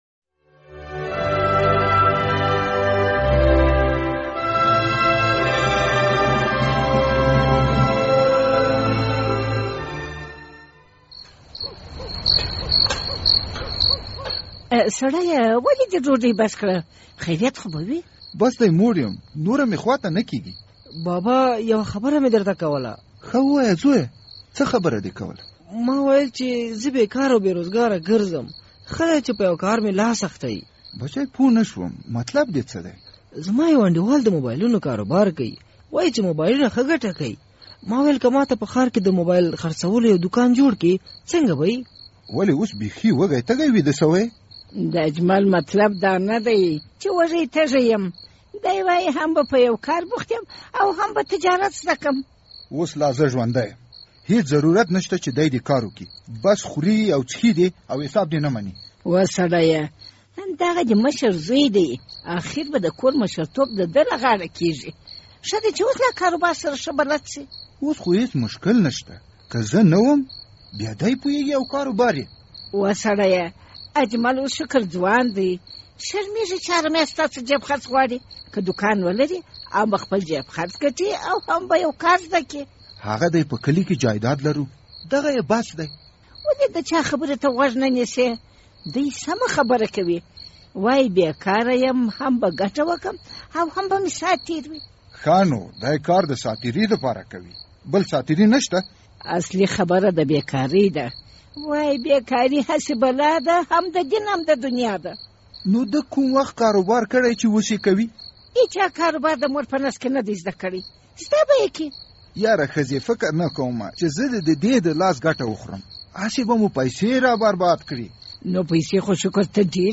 د زهرو کاروان پروګرام ډرامه